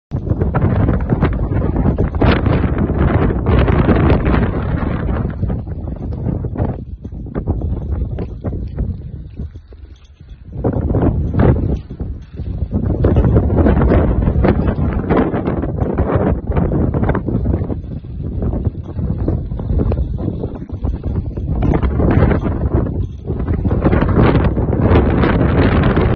从2日18时开始，
长白山天池主峰出现大到暴雨天气，